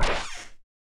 snd_steamworks_13_vent.wav